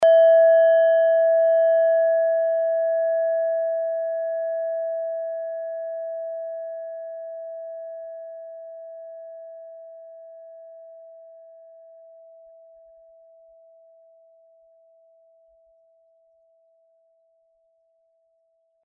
Nepal Klangschale Nr.12
Hörprobe der Klangschale
(Ermittelt mit dem Filzklöppel)
klangschale-nepal-12.mp3